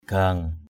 /ɡ͡ɣa:ŋ˨˩/